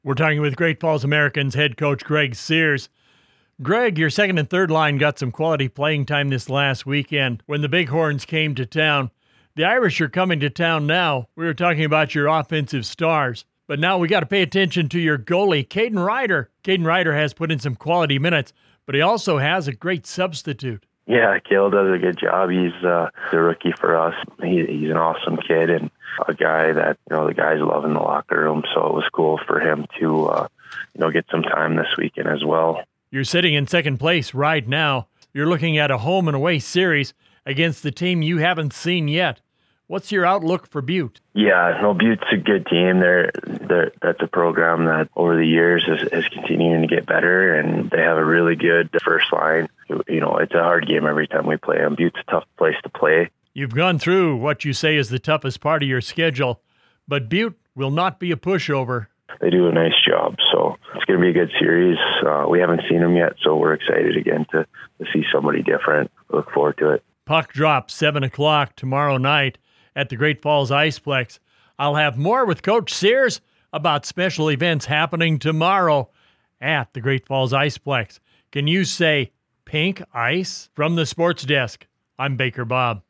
560AM KMON: Weekly Radio Interview